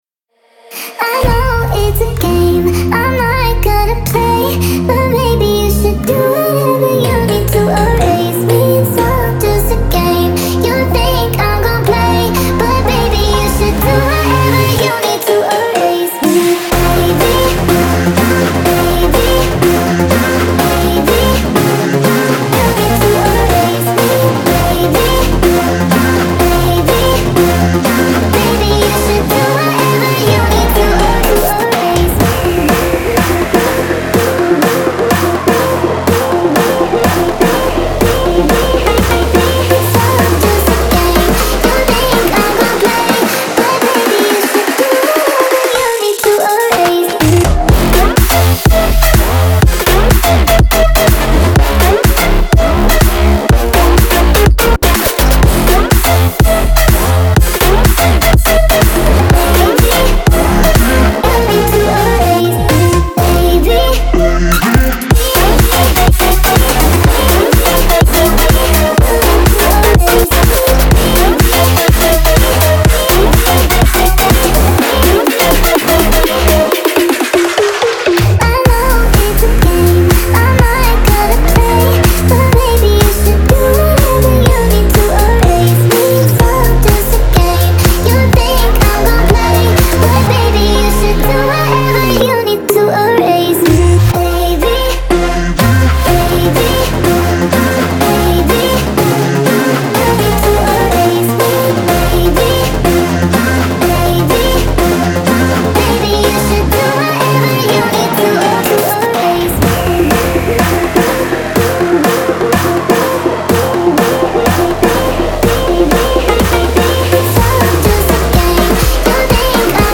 Future house, House, Dance, Epic, Funny & Running